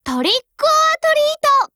贡献 ） 协议：Copyright，其他分类： 分类:少女前线:UMP9 、 分类:语音 您不可以覆盖此文件。